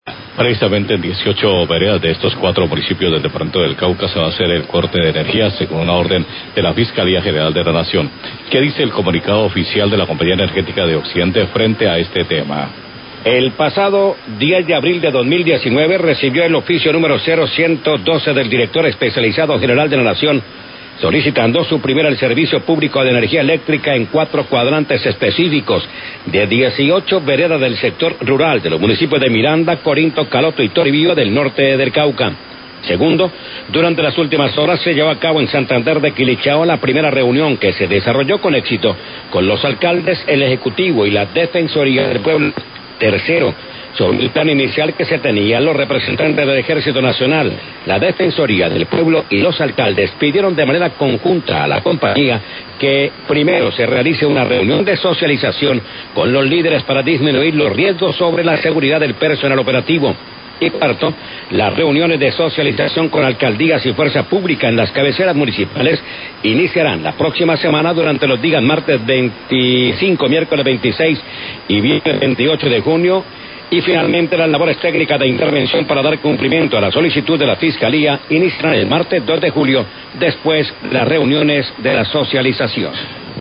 Radio
comunicado de prensa